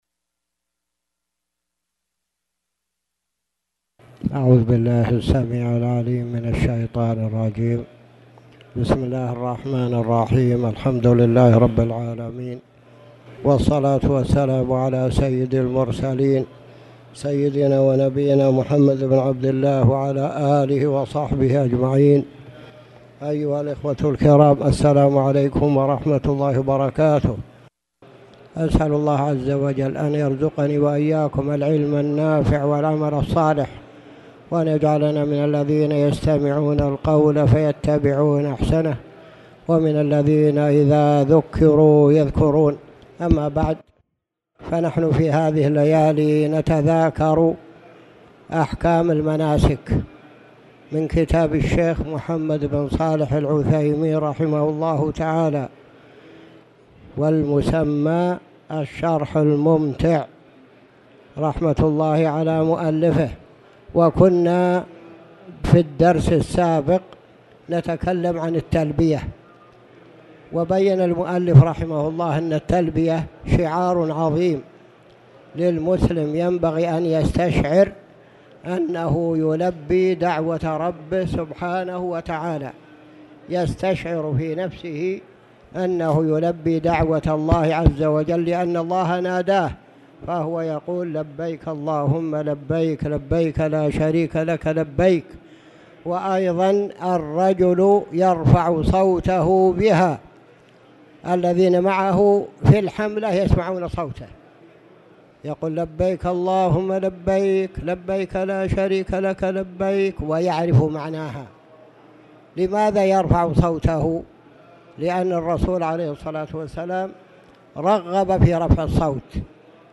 تاريخ النشر ٢٢ ذو القعدة ١٤٣٨ هـ المكان: المسجد الحرام الشيخ